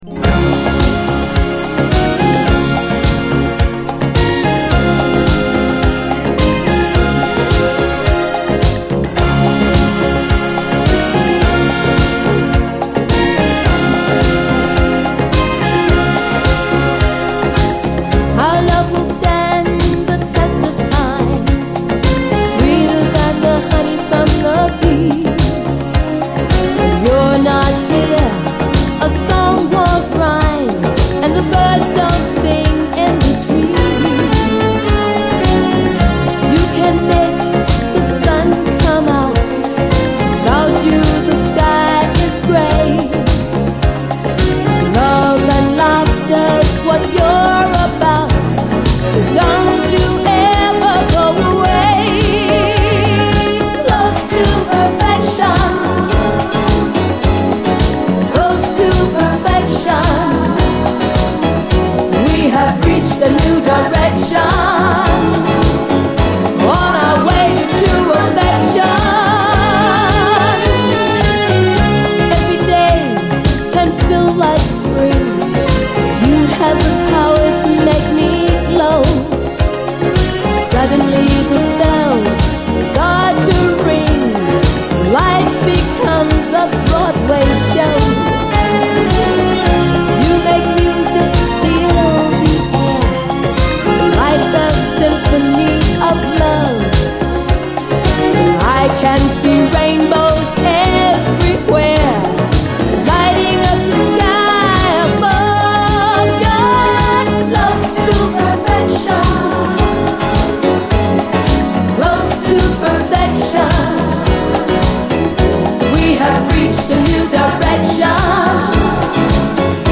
SOUL, 70's～ SOUL, DISCO, 7INCH
最高のUK産メロウ・バレアリック・ディスコ！リゾート地のビーチの夕暮れ時を連想させるビューティフル・トラック！